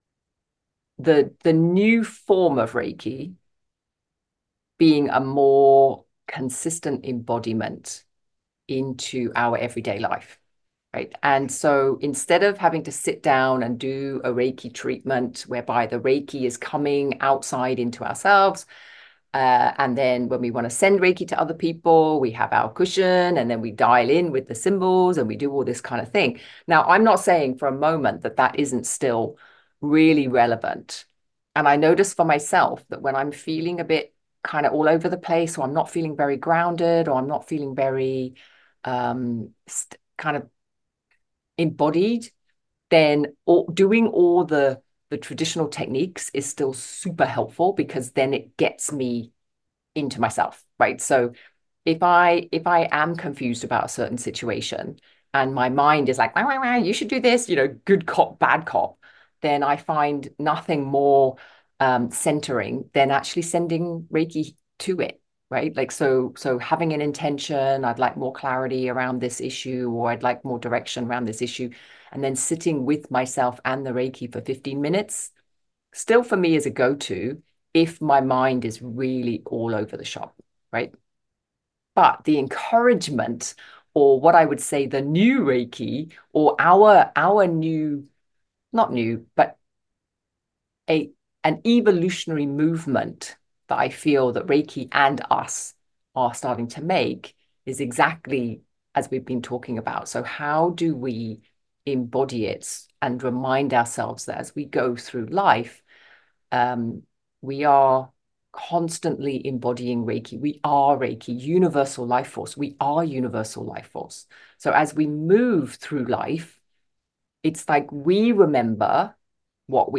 Embodying your Reiki flow - meditation and discussion
September online gathering we discuss embodiment. Rather than doing your Reiki practice daily and then stressing the rest of the day or feeling like you need to reset every single day, the aim of practice is to embody the Reiki, the universal flow into your life more and more so that you are free, enjoying life, and present to it.